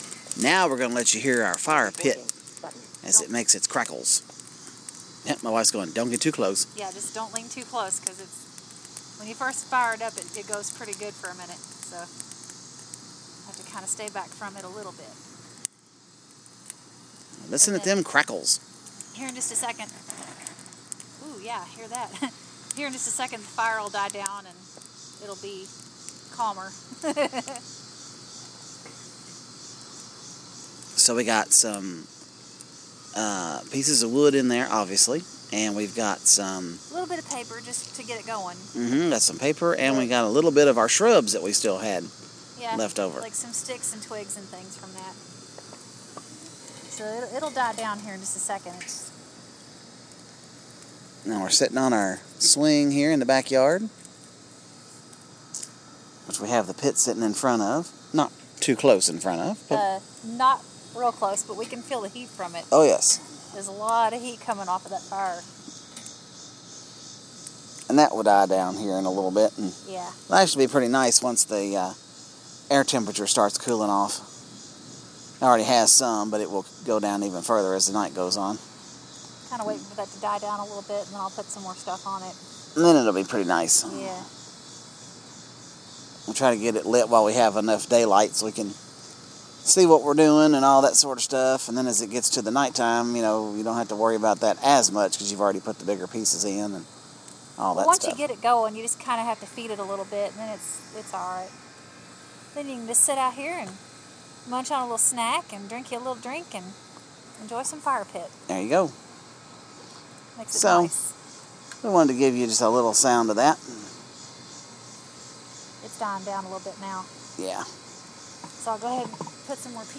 Monday night at the fire pit